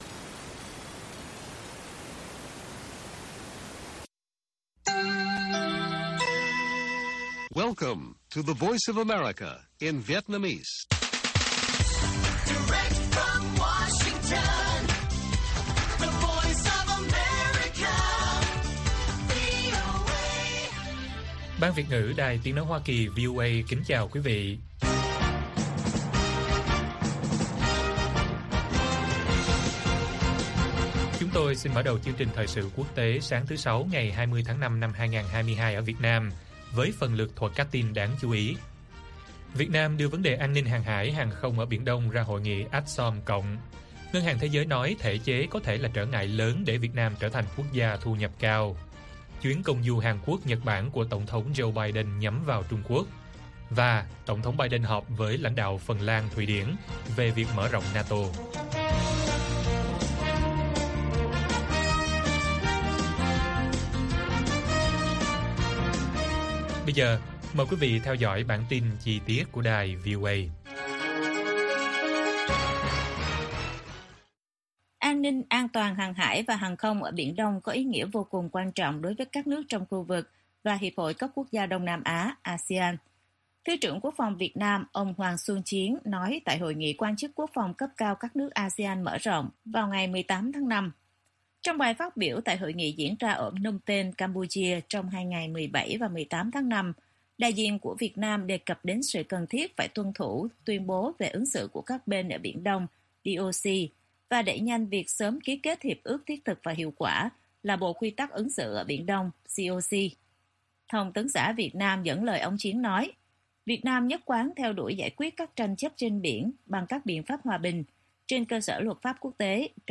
Chuyến công du Hàn Quốc, Nhật Bản của Biden nhắm vào Trung Quốc - Bản tin VOA